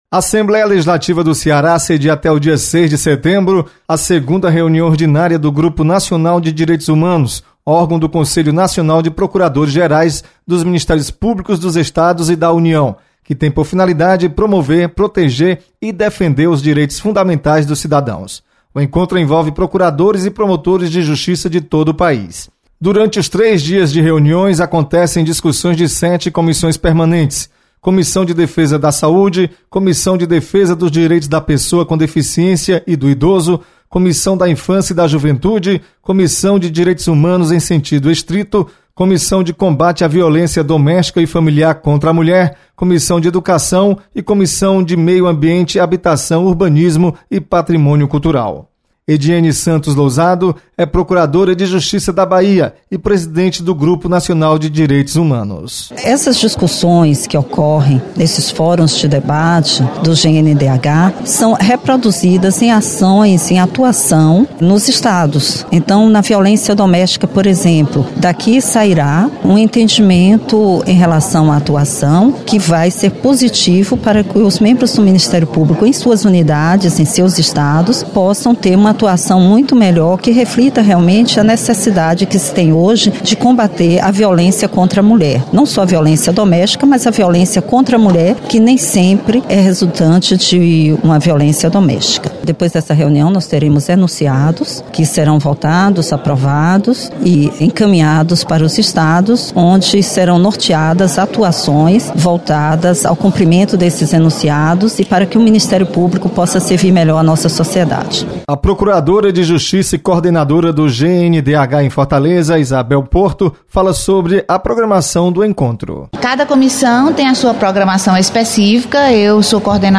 Assembleia sedia reunião sobre direitos humanos. Repórter